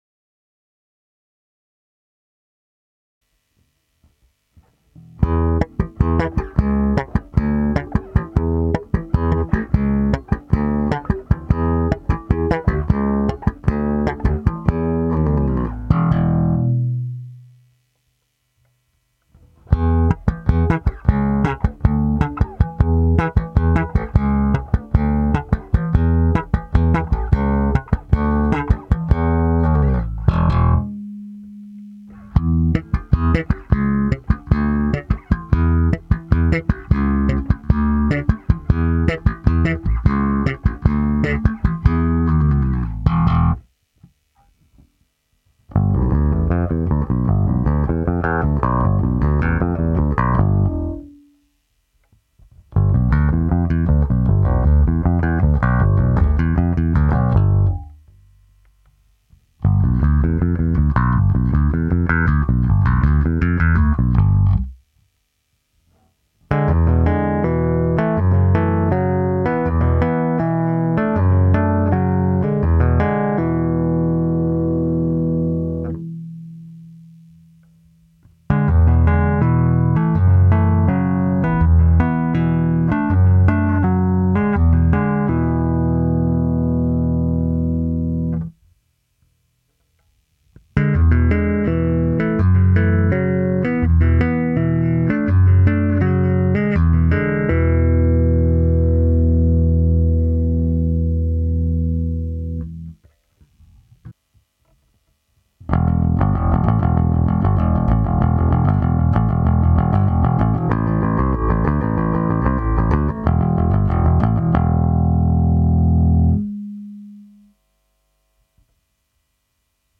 grind_bass_demo_in_line.mp3